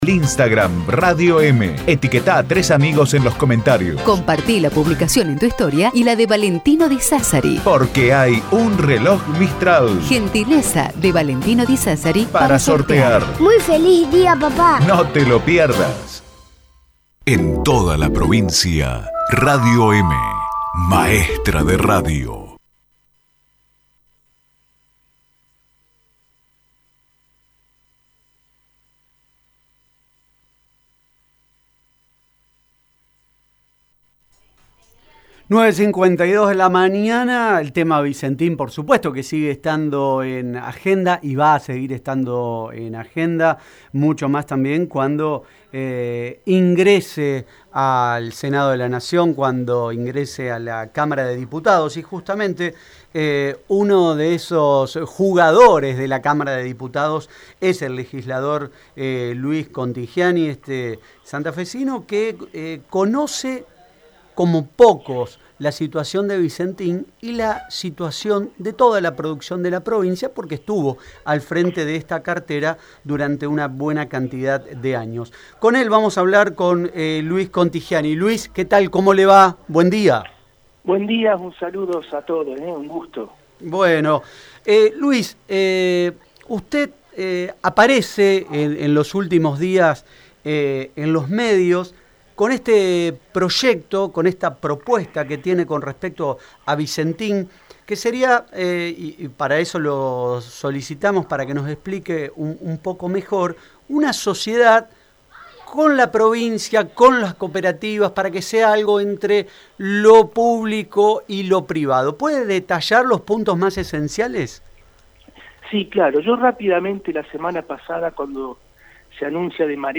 NOTA-Luis-Contigiani-Diputado-Nacional-PS.mp3